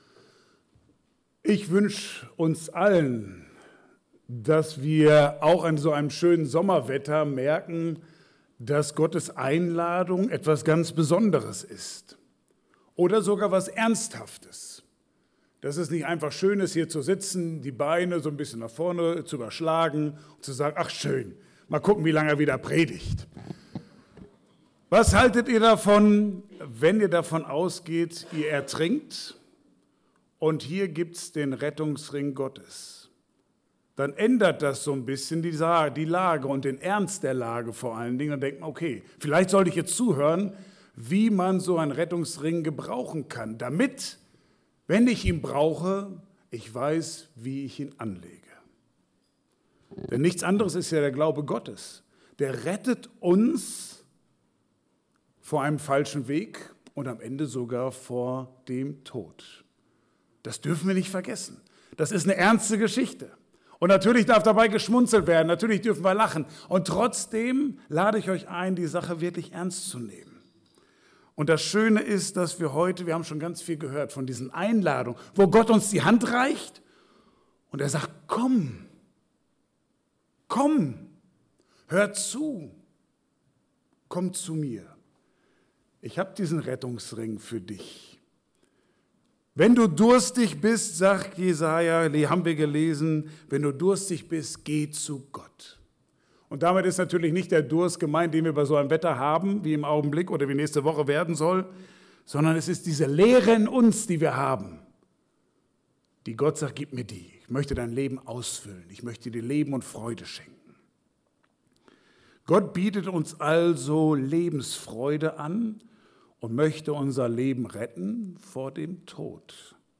Passage: Jesaja 55,1-5 Dienstart: Gottesdienst « 2.